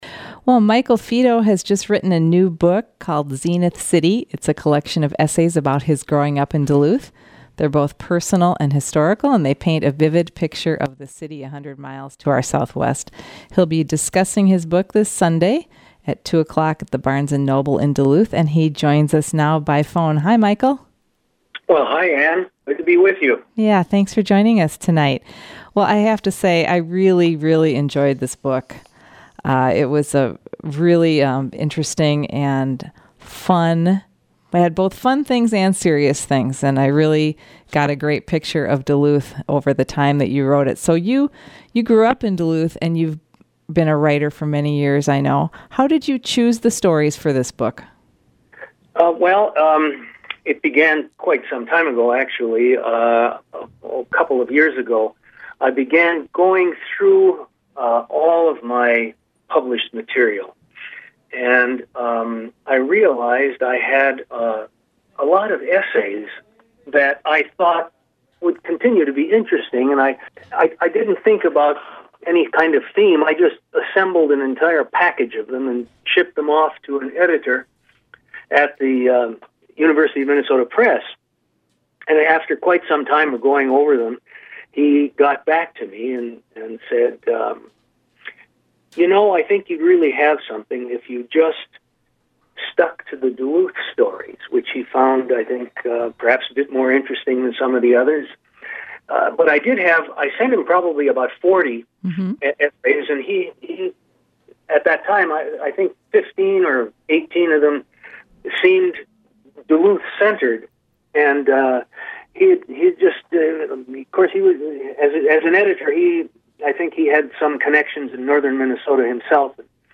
He reads a brief passage from one very funny essay, as well.